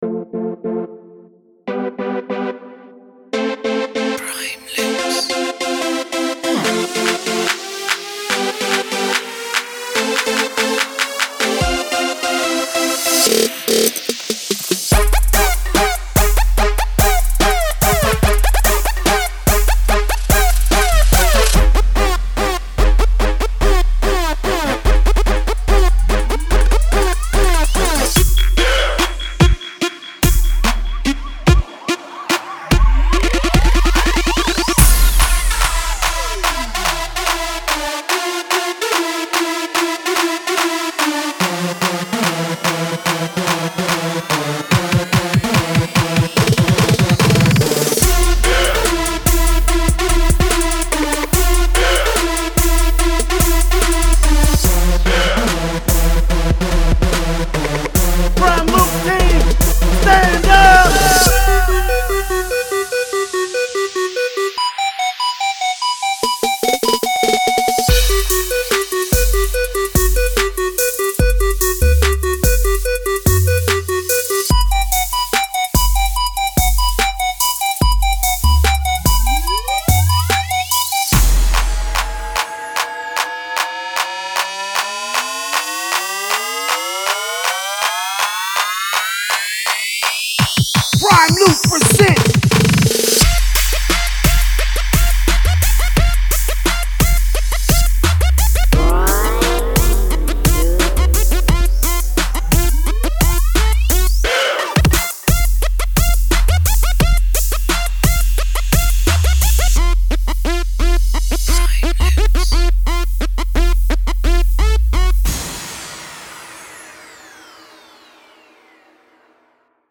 3. Trap
节奏范围从140 BPM到174 BPM，但是您可以在混音中将拍子设定为经线速度，方法是抓住琴头的末端并调整其长度。
达到疯狂的24位高清质量，这些样本不可能被破坏，无论您选择切碎和更改它们的数量如何。